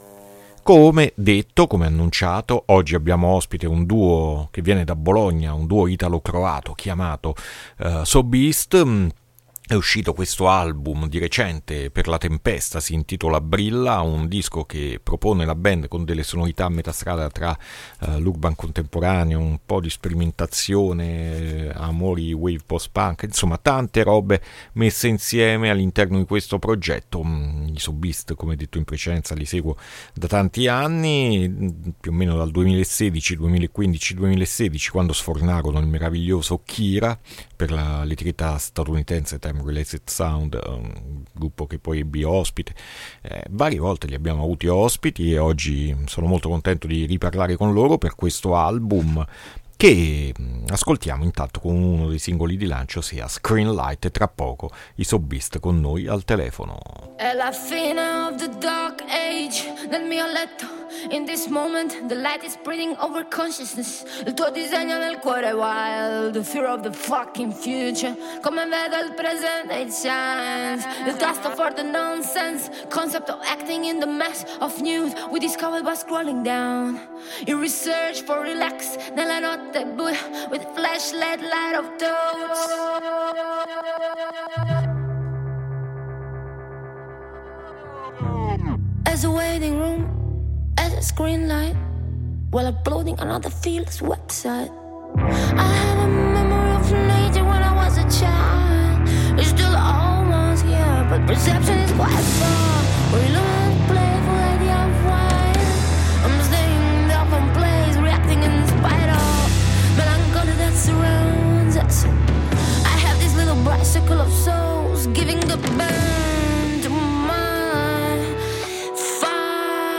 INTERVISTA SO BEAST AD ALTERNITALIA 7-4-2023